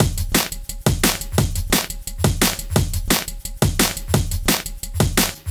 Index of /neuro/Stanza/Drums/Drum Loops